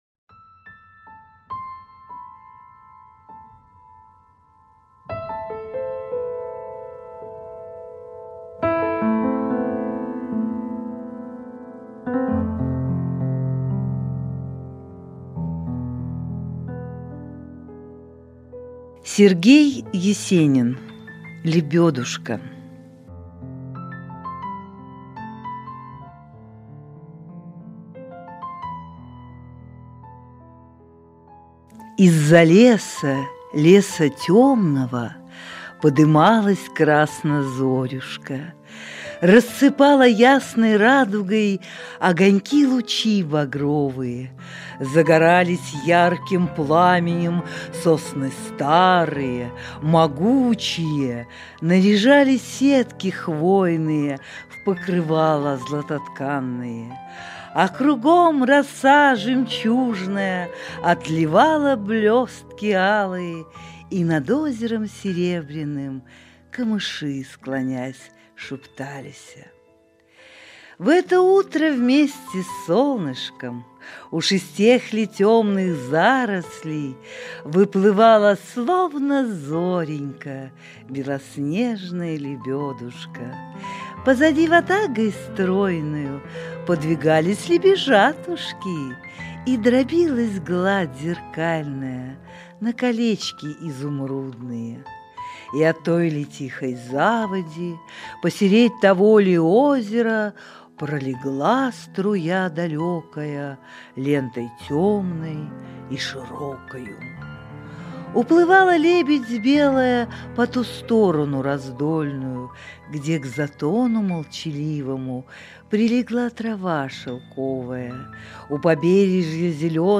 Logos-Literaturnye-chteniya.-Sergej-Esenin-Lebedushka-stih-club-ru.mp3